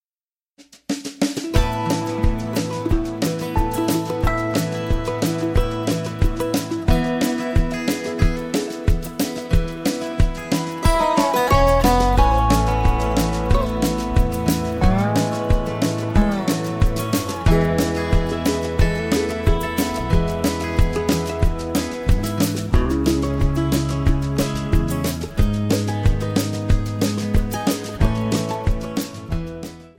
MPEG 1 Layer 3 (Stereo)
Backing track Karaoke
Country, 2000s